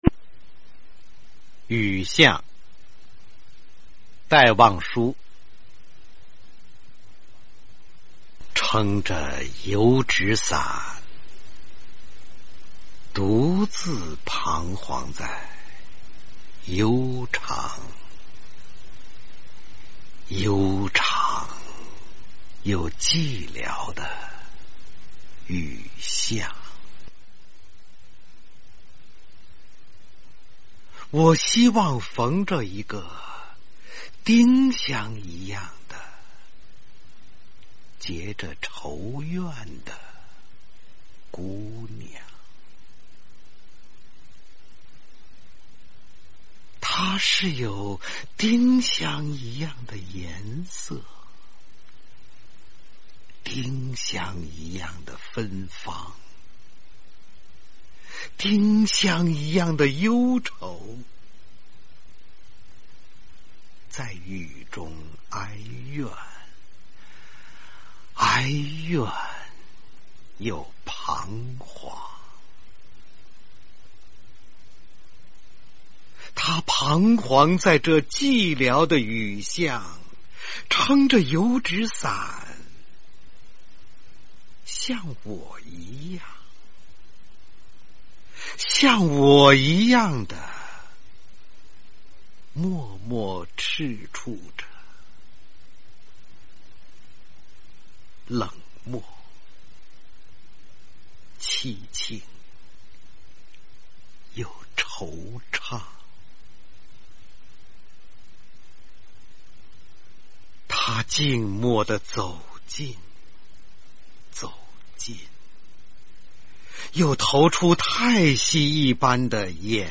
雨巷-读诵